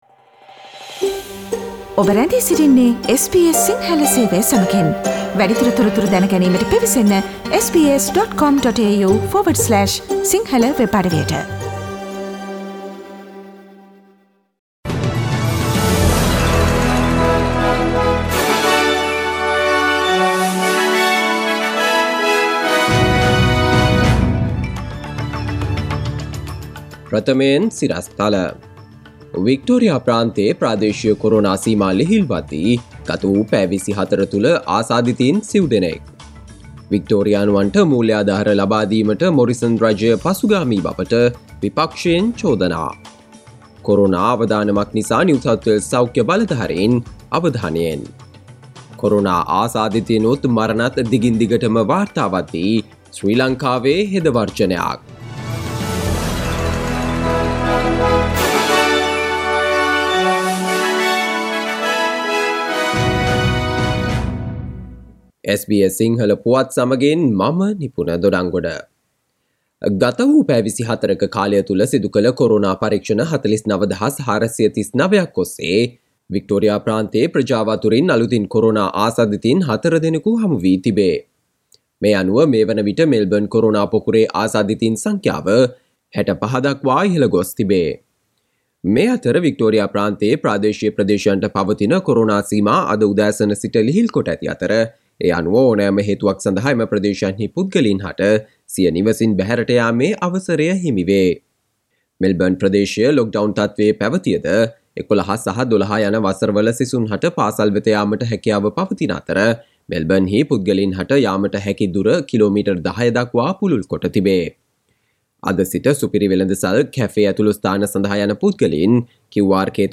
Here are the most prominent Australian and Sri Lankan news highlights from SBS Sinhala radio daily news bulletin on Friday 04 June 2021.